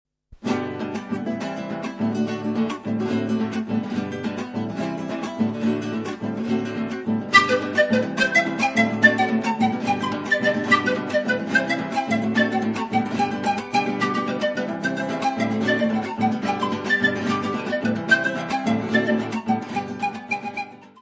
: stereo; 12 cm
Área:  Tradições Nacionais